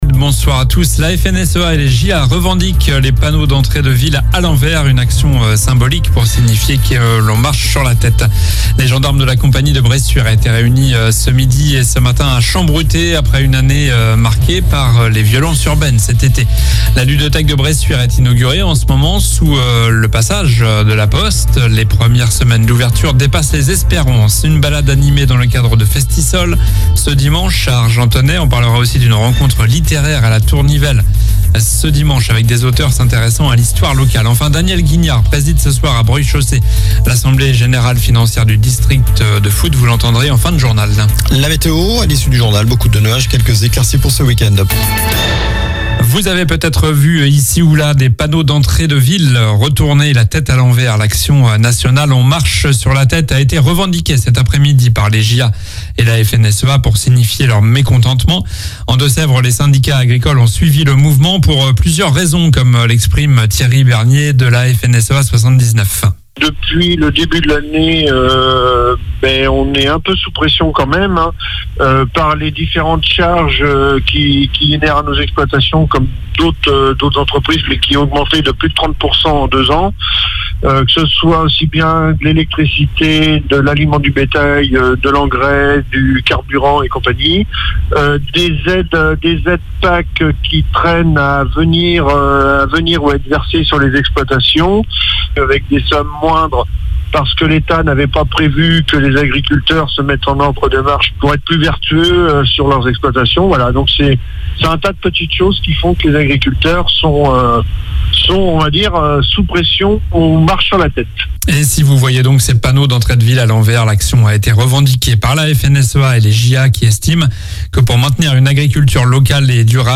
Journal du vendredi 17 novembre (soir)